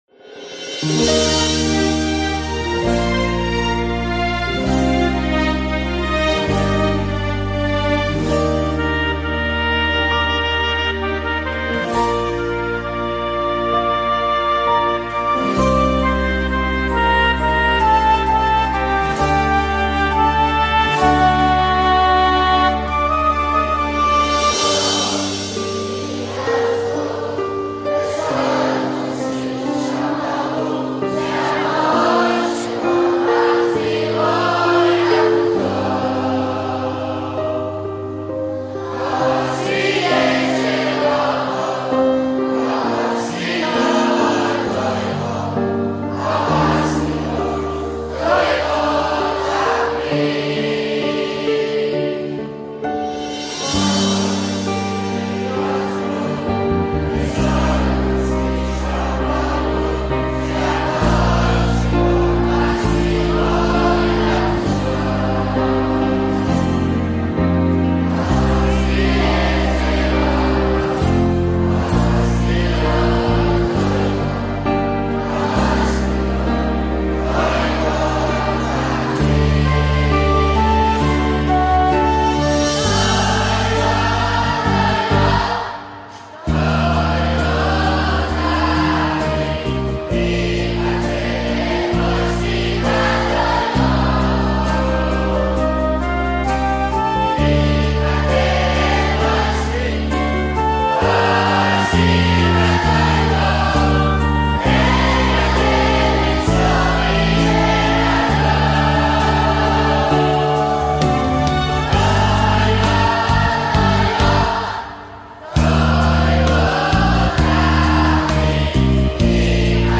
רגע השיא היה בצילום – כאשר התלמידים, בעיניים בורקות  שרו יחד את מילות התורה שנטמעו בהם לאורך המסע.